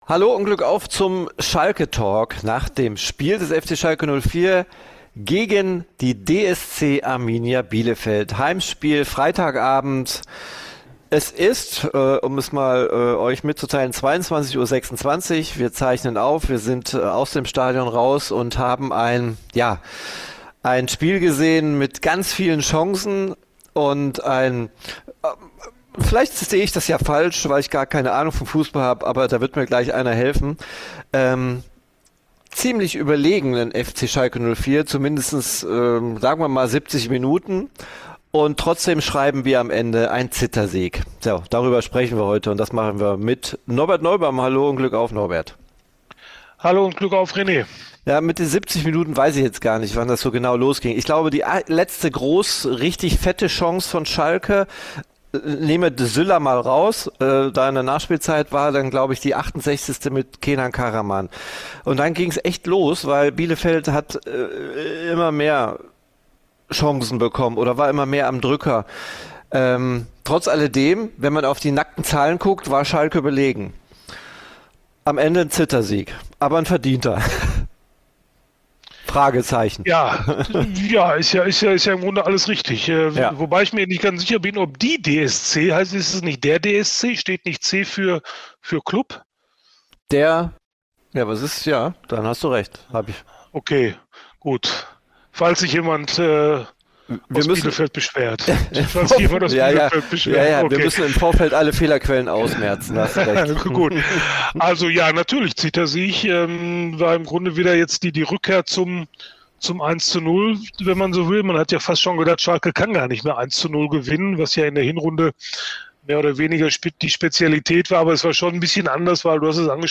Gute Arbeit lohnt sich - Verdienter „Zittersieg“ Der Schalke-Talk nach Arminia Bielefeld ~ Schalke POTTcast - Der Experten-Talk zu S04 Podcast